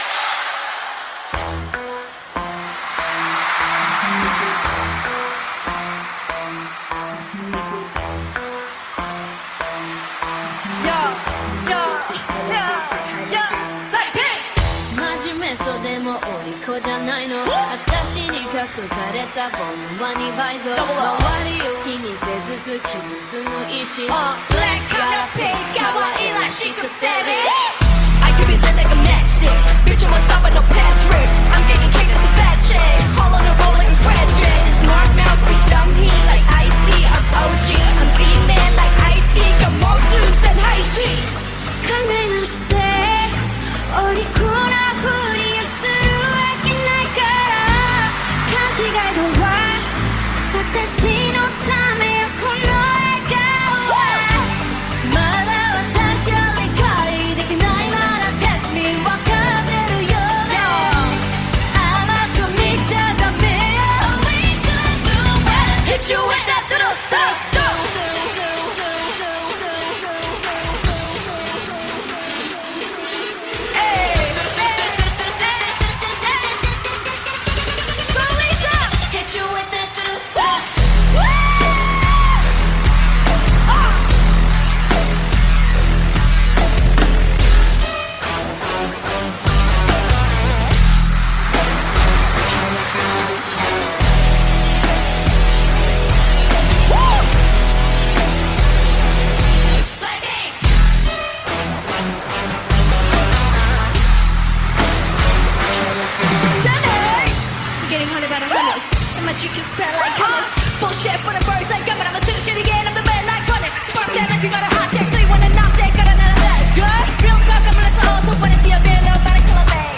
ریمیکس اجرای زنده